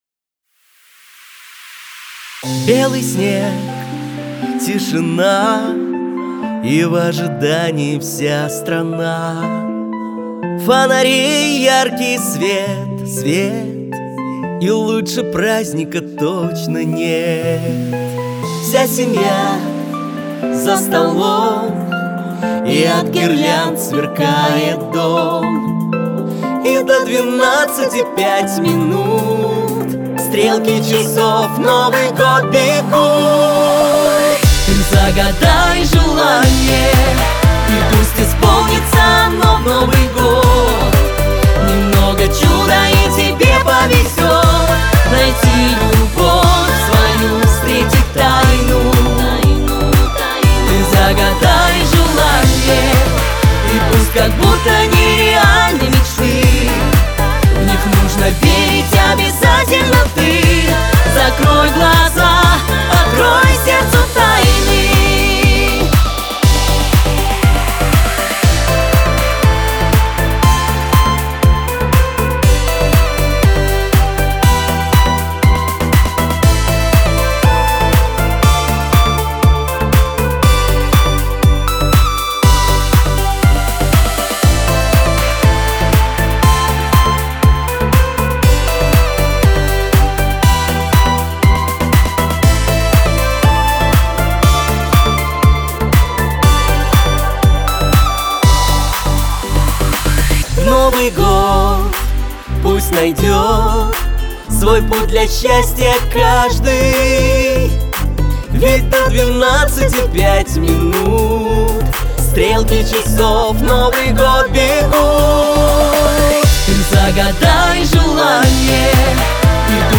Всё,кроме женского вокала,МОЁ!!!